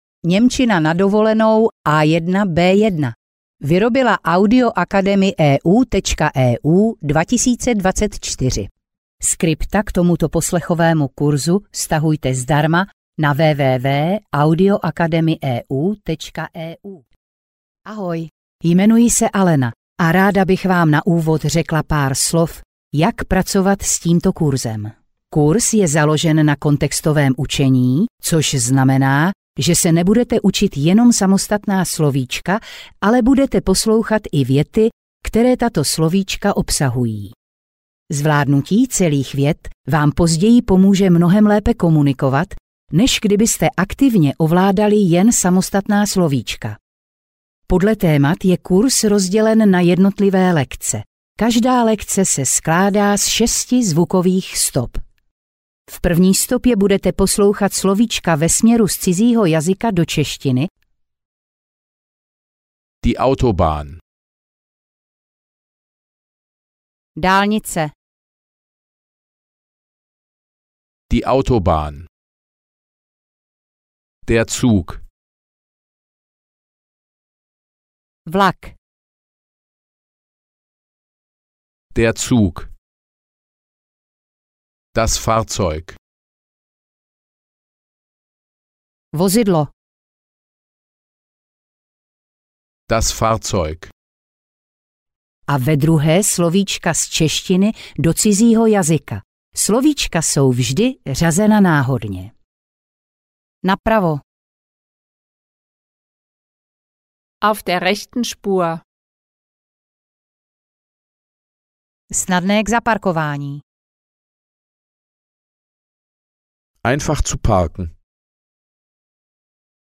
Němčina na dovolenou A1-B1 audiokniha
Ukázka z knihy